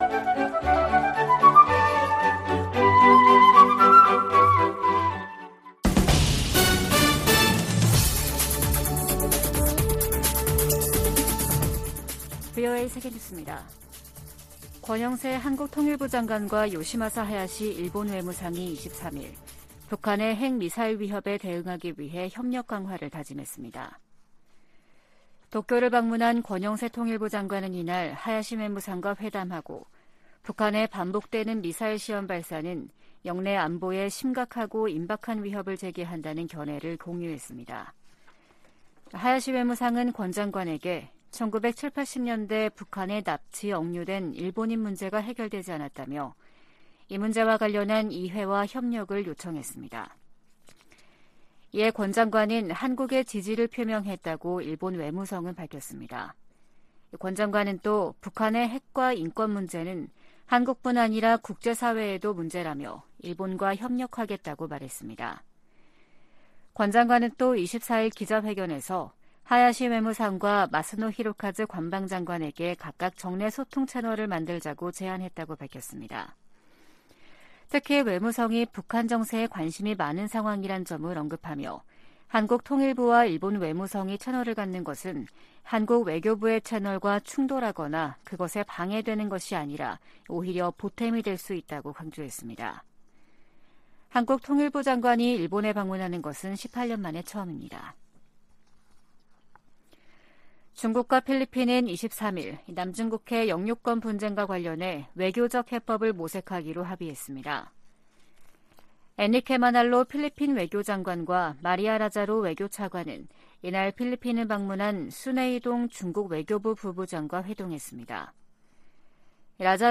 VOA 한국어 아침 뉴스 프로그램 '워싱턴 뉴스 광장' 2023년 3월 25일 방송입니다. 북한이 '핵 무인 수중 공격정' 수중 폭발시험을 진행했다고 대외관영 매체들이 보도했습니다. 로이드 오스틴 미 국방장관은 북한을 지속적인 위협으로 규정하며 인도태평양 지역에서 방위태세를 강화하고 훈련 범위와 규모도 확대하고 있다고 밝혔습니다. 윤석열 한국 대통령은 '서해 수호의 날' 기념사에서 북한의 무모한 도발에는 대가를 치르게 하겠다고 강조했습니다.